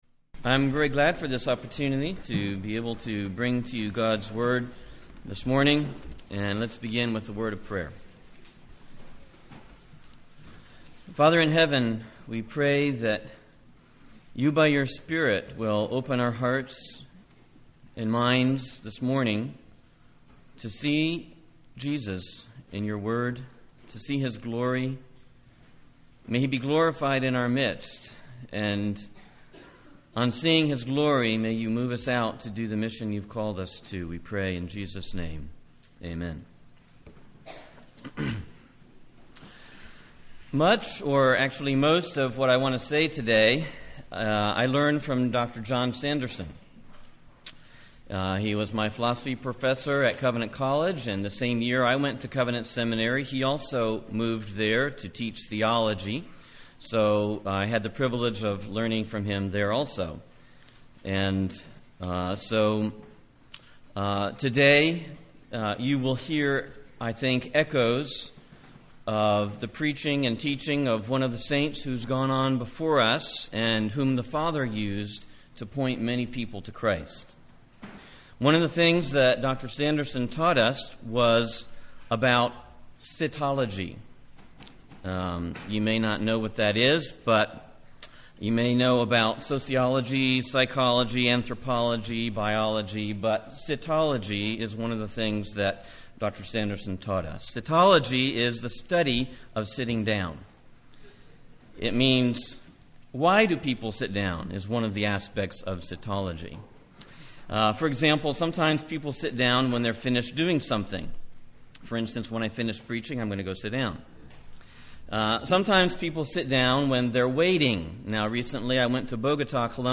A sermon from the book of Hebrews. Preached October 31, 2010 at St. Elmo Presbyterian Church in Chattanooga.